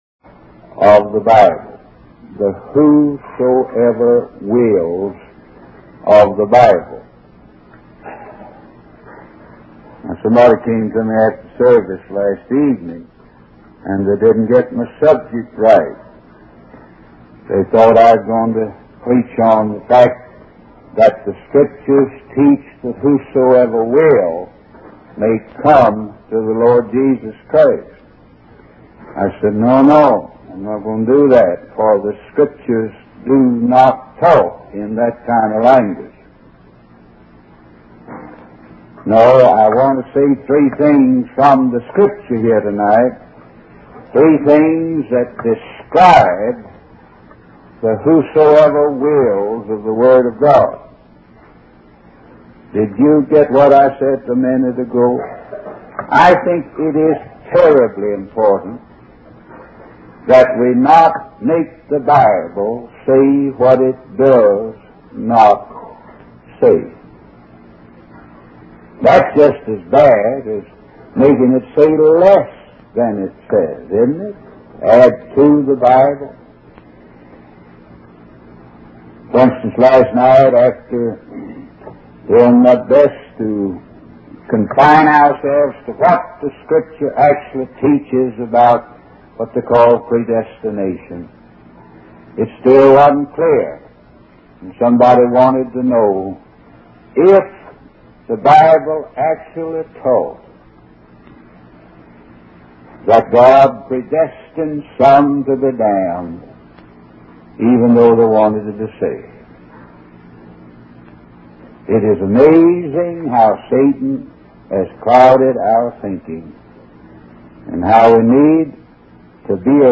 In this sermon, the preacher emphasizes the importance of continuously seeking and committing to Jesus Christ. He compares the concept of a mourner's bench to the idea of having a mourner's bench in one's heart, representing a constant expression of faith and growth in the Christian journey.